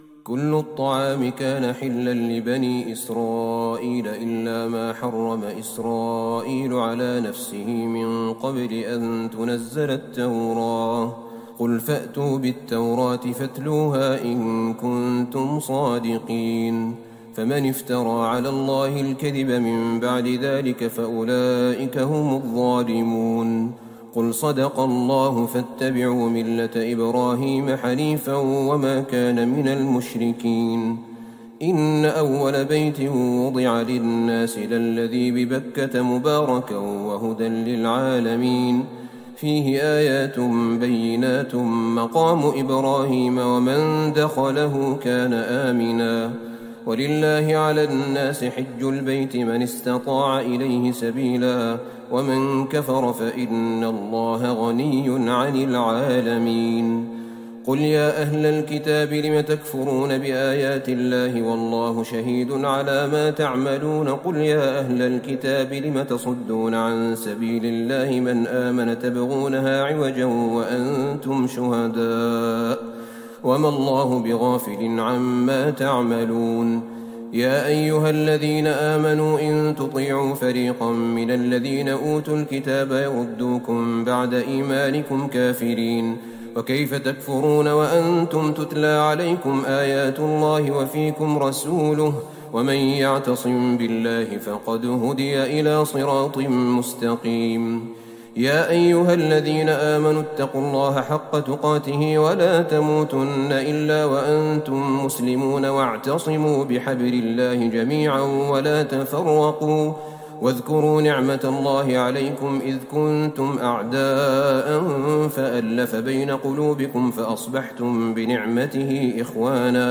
ليلة ٥ رمضان ١٤٤١هـ من سورة آل عمران { ٩٣-١٥٨ } > تراويح الحرم النبوي عام 1441 🕌 > التراويح - تلاوات الحرمين